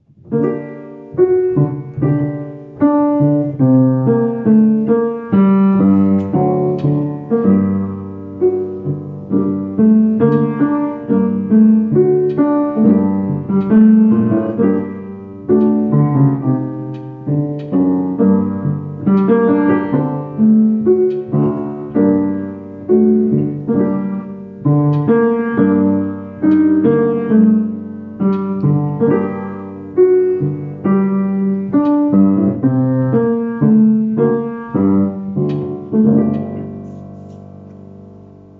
Just the piano
C1-Winter-accompaniment.mp3